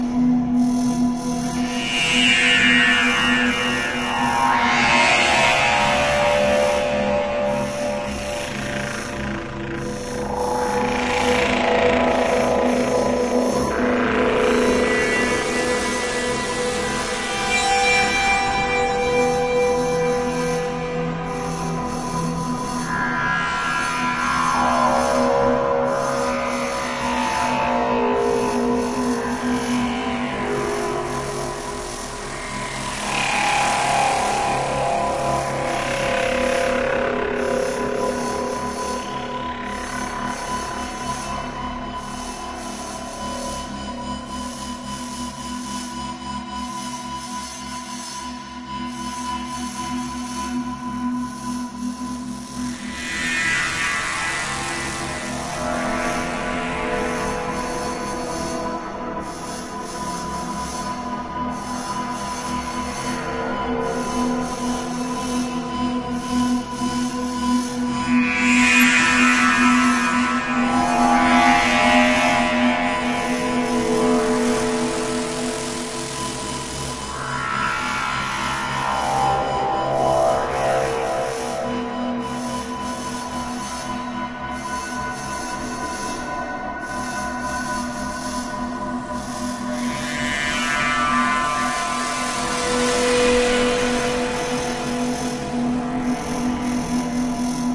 太空小说音效 " 03883 诡异的电音环境
描述：以电子方式创造的环境无人机
Tag: 未来 氛围 backgrou第二 循环 无人驾驶飞机 黑暗 电动